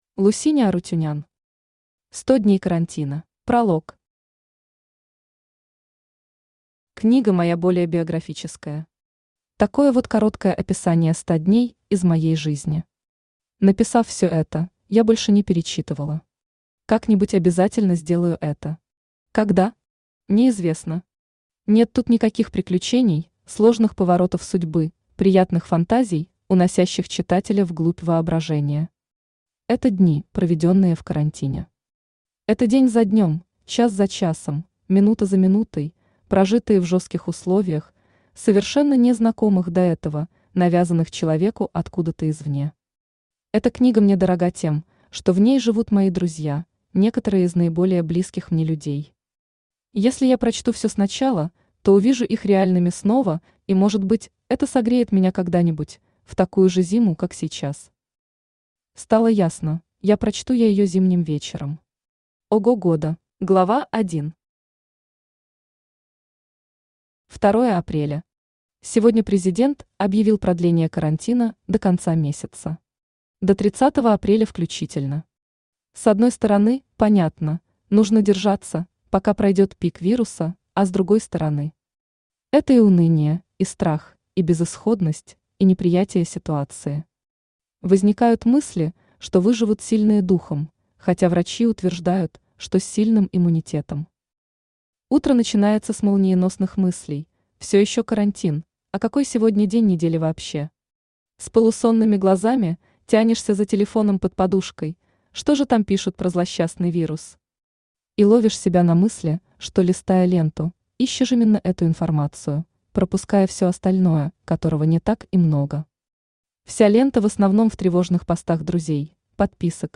Aудиокнига 100 дней карантина Автор Лусине Робертовна Арутюнян Читает аудиокнигу Авточтец ЛитРес. Прослушать и бесплатно скачать фрагмент аудиокниги